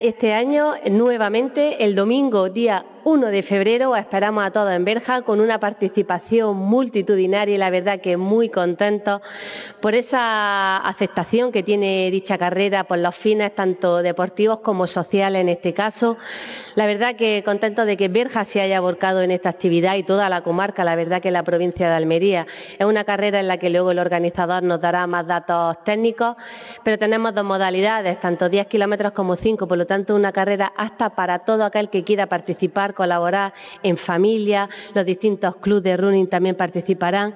28-01_berja_diputada.mp3.mp3